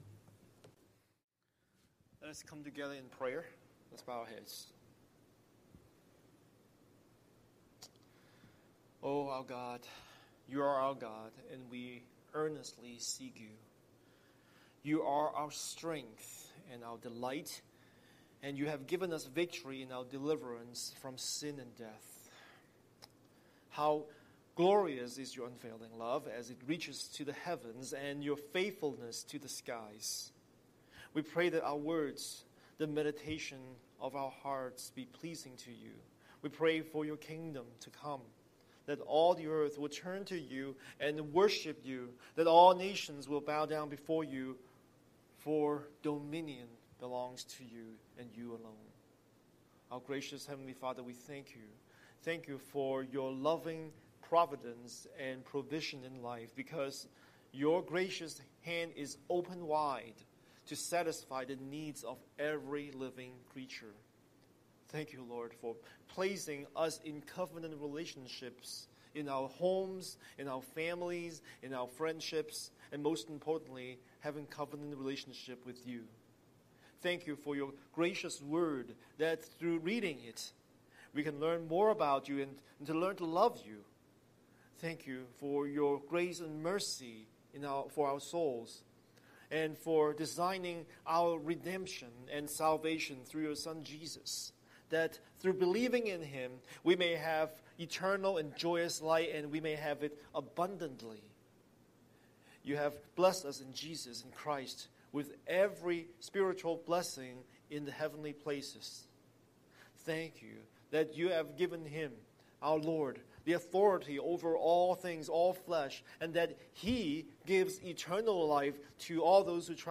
Scripture: Galatians 5:13-21 Series: Sunday Sermon